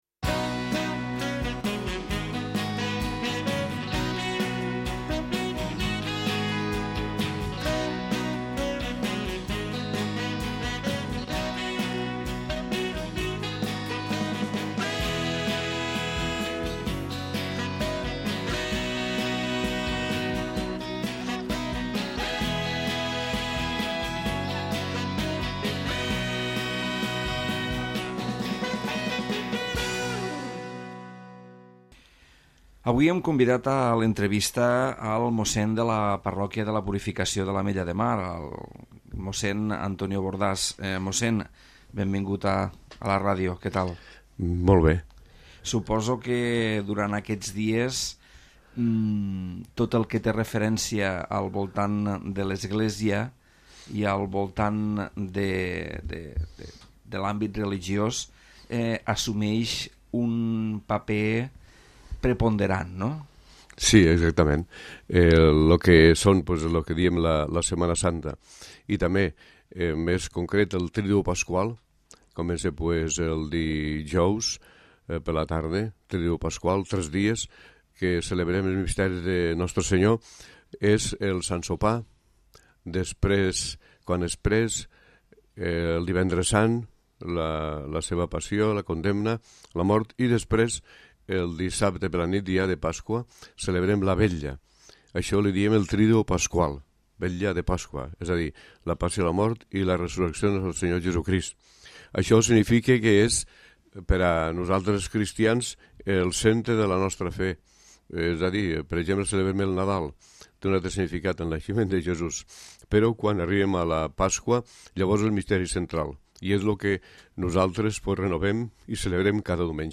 Entrevista mossèn